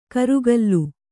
♪ karugallu